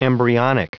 Prononciation du mot embryonic en anglais (fichier audio)
Prononciation du mot : embryonic